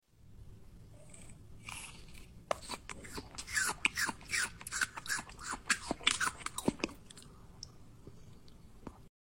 Kiwi 🥝 Eating Asmr Your Sound Effects Free Download